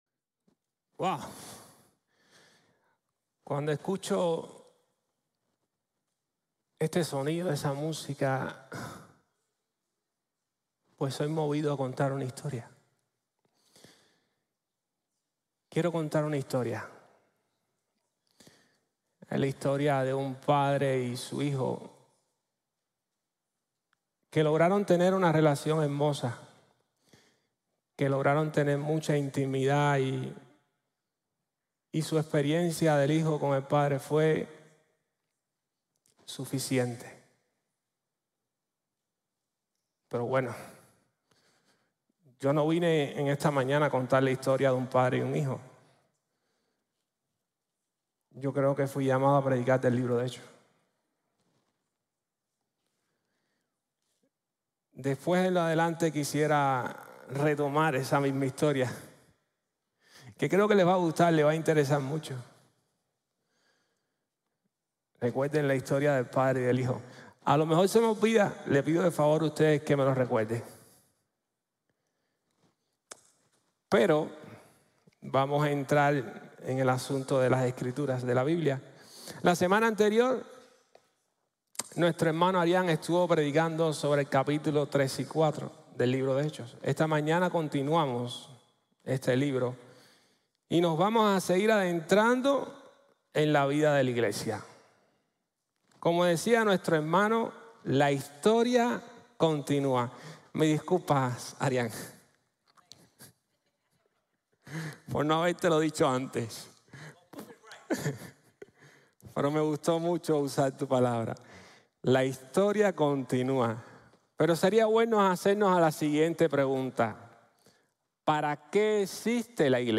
La Iglesia refleja el carácter de Dios | Sermon | Grace Bible Church